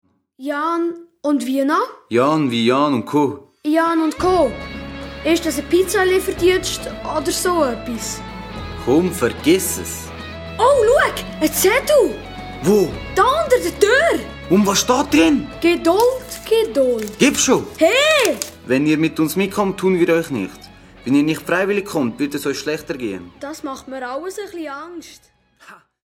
Hörspiel-CD mit Download-Code